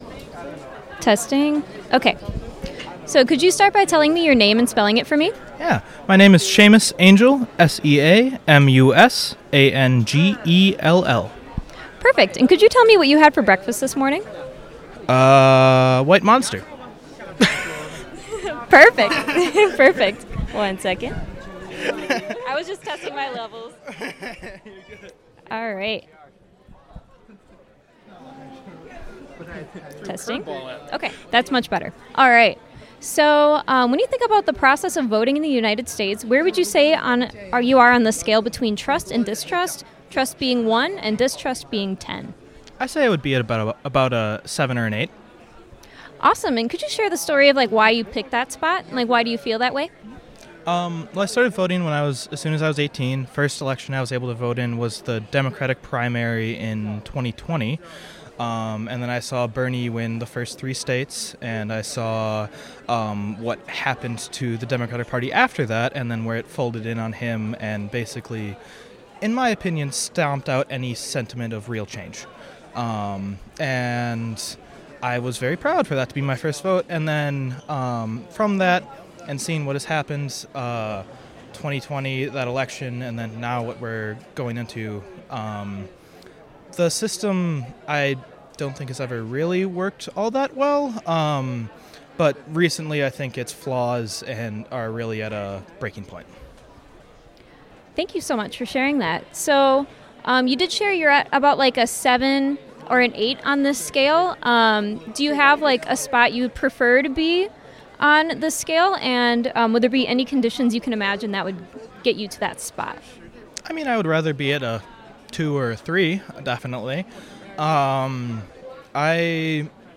Location UWM Student Union